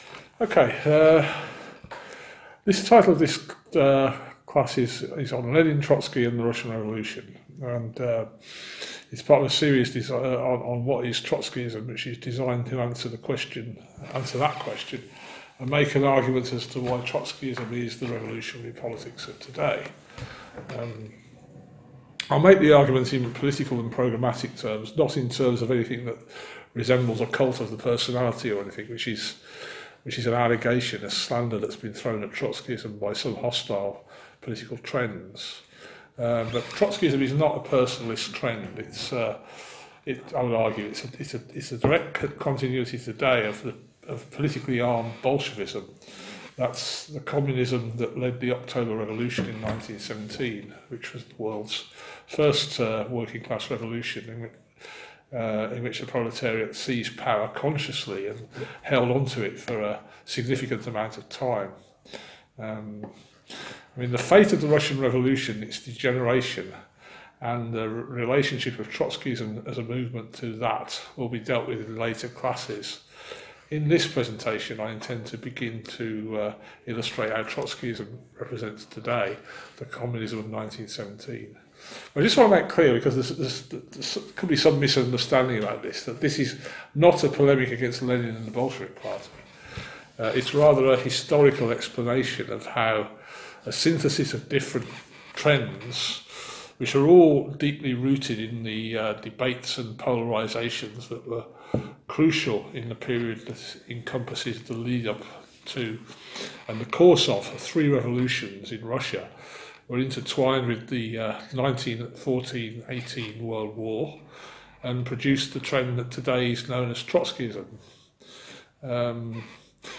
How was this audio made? In order to educate new comrades around us, Socialist Fight are running a series of educationals on Trotskyism. Below is an edited podcast of the first in the series, which took place on 29 June, on Lenin, Trotsky and the Russian Revolution.